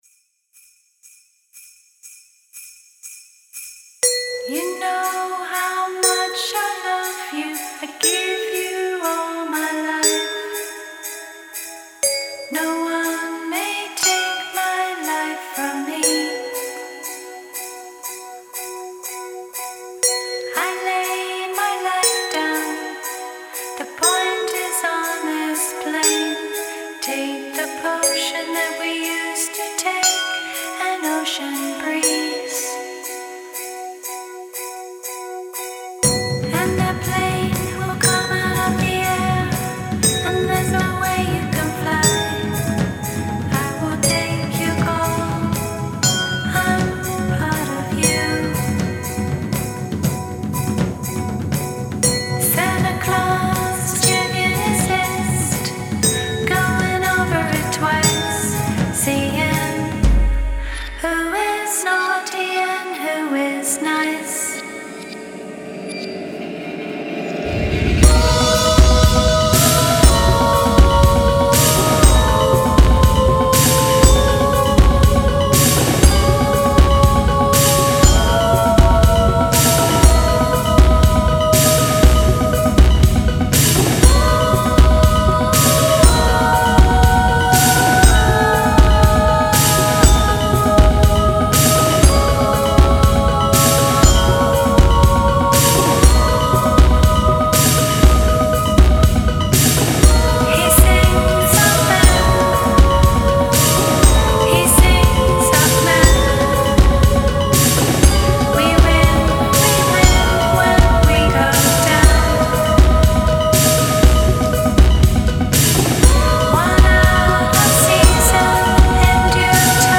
NYC electronic duo